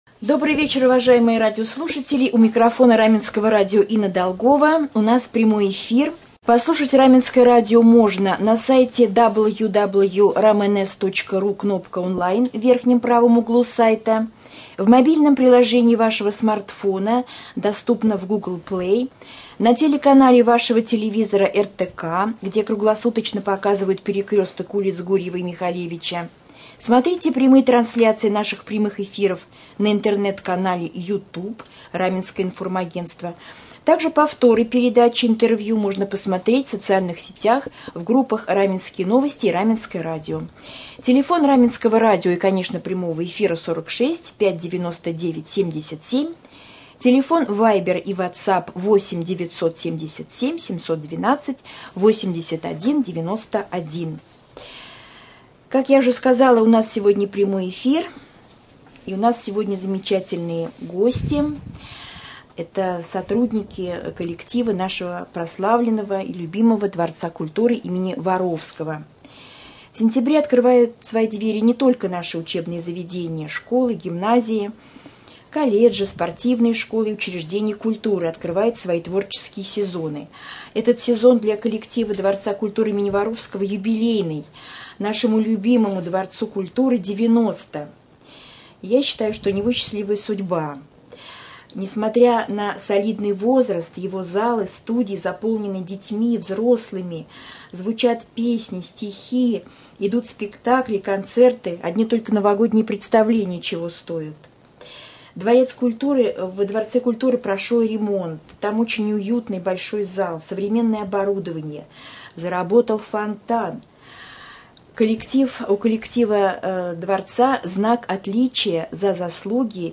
Pryamoj-efir-2.mp3